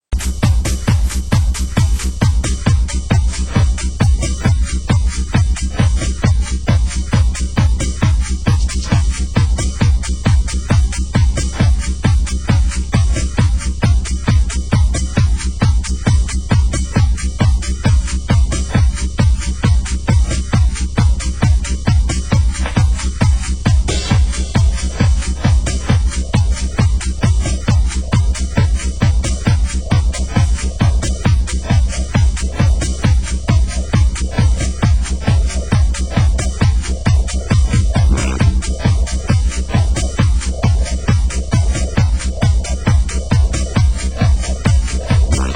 Genre: Progressive
Genre: UK Techno